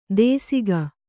♪ dēsiga